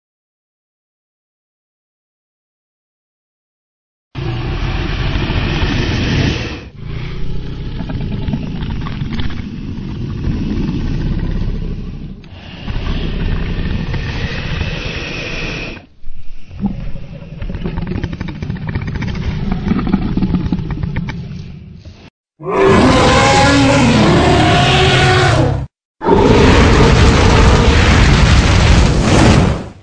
دانلود صدای اژدها 2 از ساعد نیوز با لینک مستقیم و کیفیت بالا
جلوه های صوتی